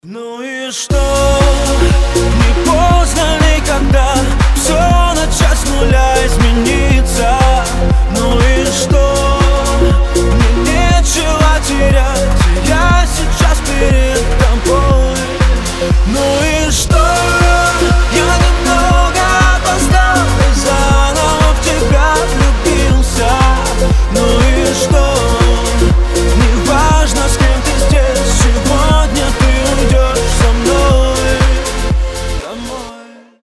• Качество: 320, Stereo
поп
мужской вокал
громкие